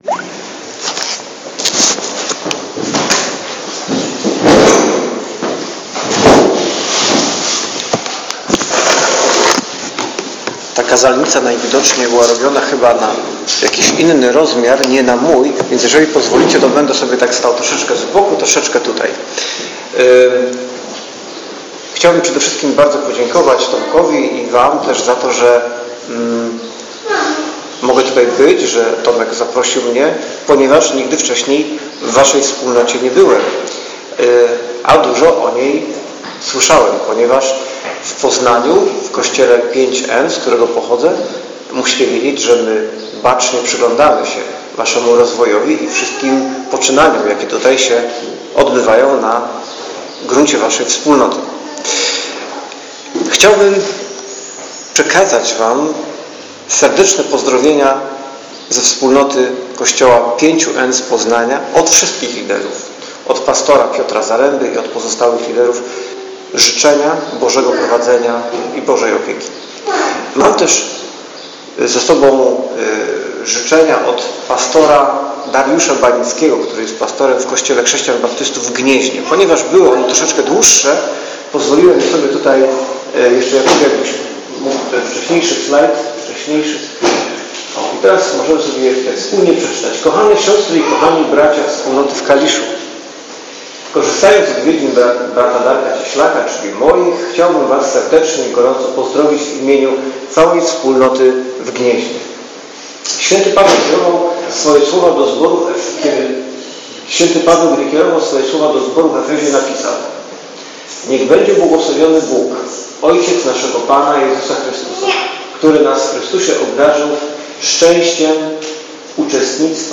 Posłuchajcie kazania pt."Porwane sieci".